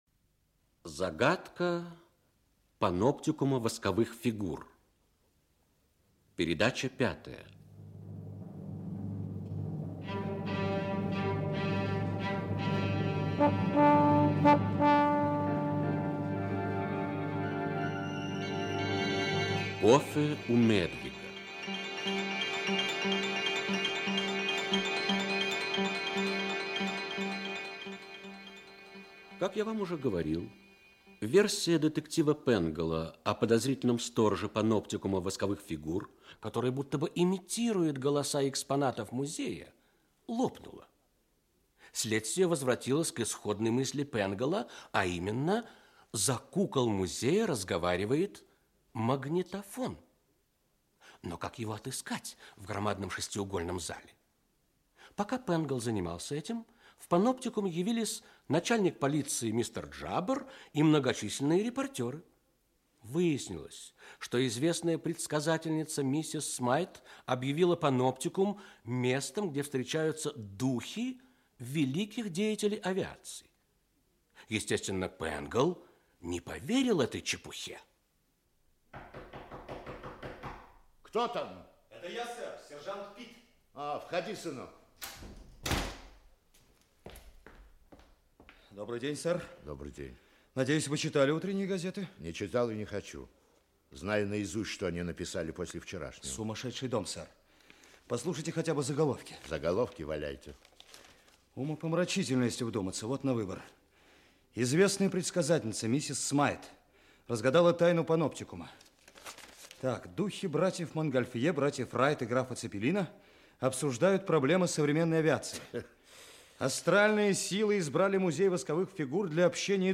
Аудиокнига Загадка паноптикума восковых фигур. Часть 5. Кофе у Мэдвига | Библиотека аудиокниг